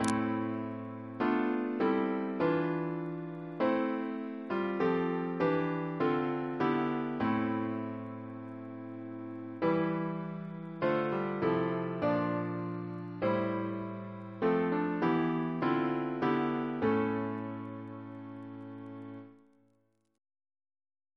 Double chant in A minor Composer: Richard Wayne Dirksen (1921-2003), Organist of Washington Cathedral Reference psalters: H1982: S287